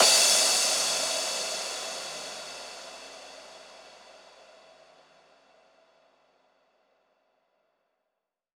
VTS1 Space Of Time Kit Drums & Perc
VTS1 Space Of Time Kit 140BPM Crash WET.wav